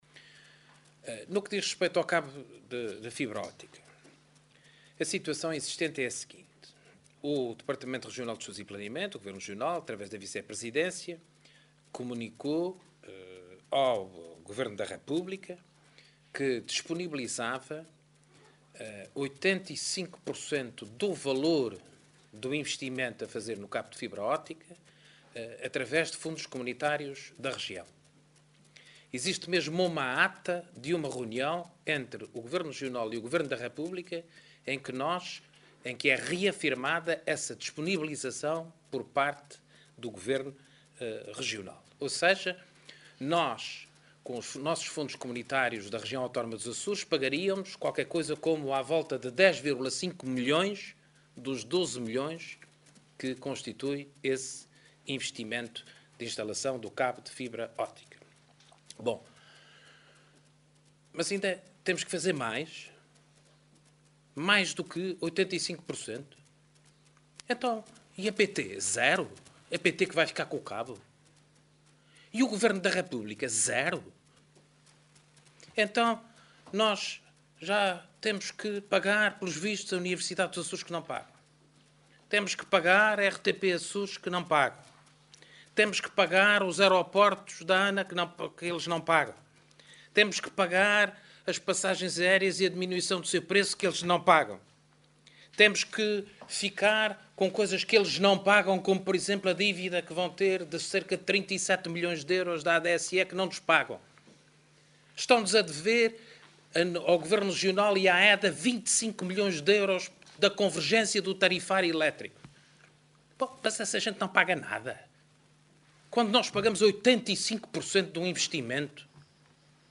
Carlos César garantiu ao Conselho de Ilha das Flores – com o qual o Governo Regional esteve reunido no âmbito da visita estatutária de dois dias que está a efetuar – que o impasse à volta da extensão do cabo de fibra ótica ao Grupo Ocidental ficará resolvido “este mês”.